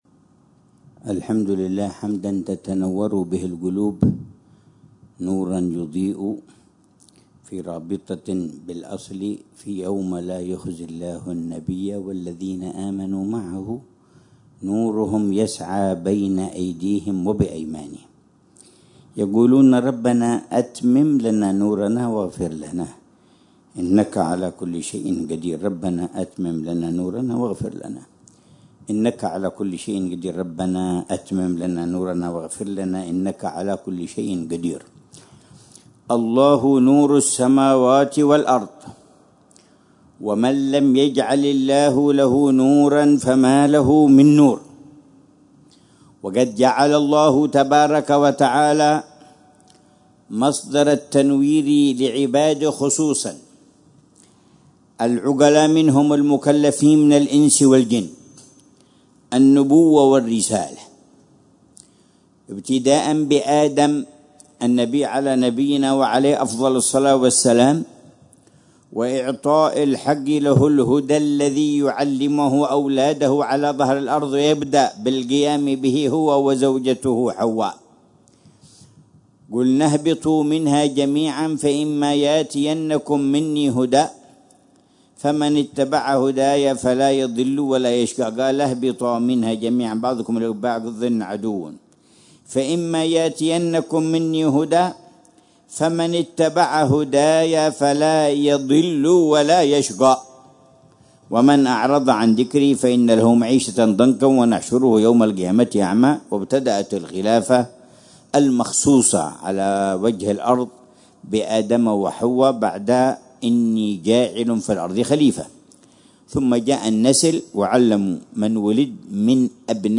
محاضرة العلامة الحبيب عمر بن محمد بن حفيظ في جلسة الجمعة الشهرية الـ60، في الساحة الشرقية لجامع السعيد، باستضافة حارتي الروضة والسعيد بمدينة تريم، ليلة السبت 18 ذو الحجة 1446هـ، بعنوان: